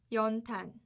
연탄1煉炭 [연:-]